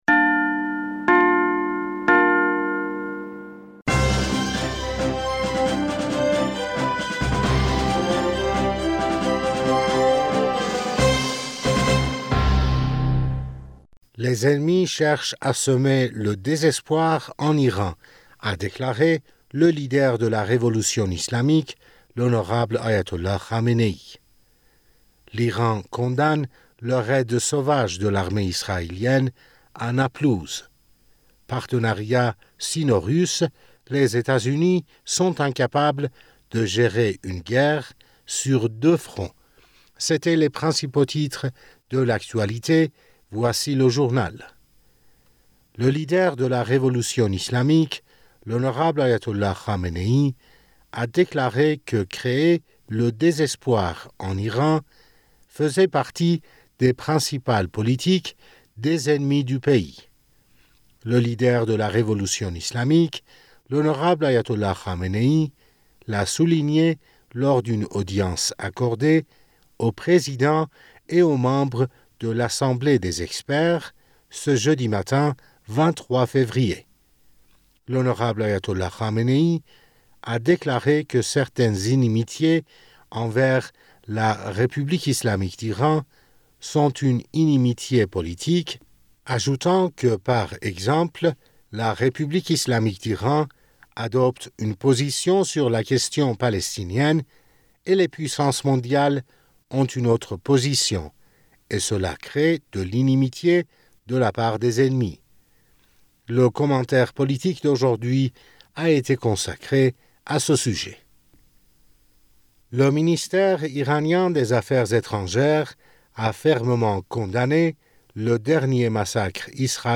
Bulletin d'information du 23 Février